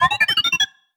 sci-fi_driod_robot_emote_beeps_04.wav